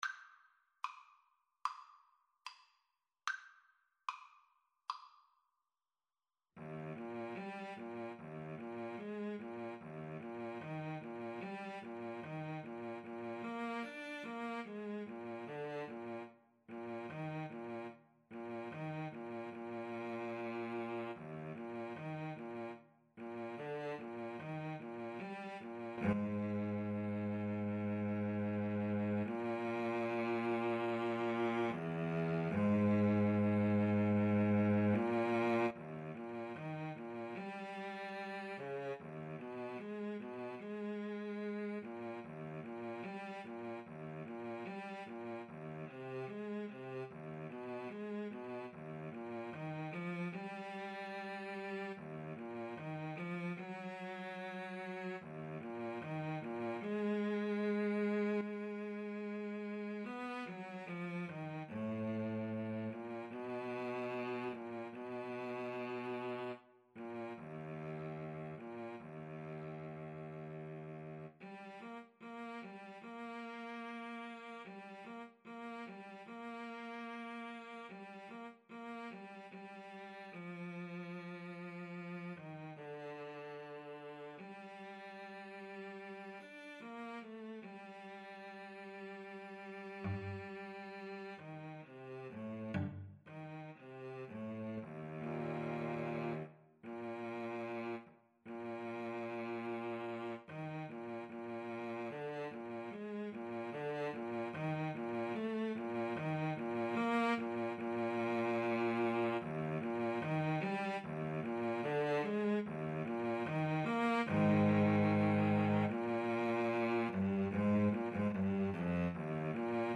Play (or use space bar on your keyboard) Pause Music Playalong - Player 1 Accompaniment reset tempo print settings full screen
Eb major (Sounding Pitch) (View more Eb major Music for Cello Duet )
=74 Andante moderato (View more music marked Andante Moderato)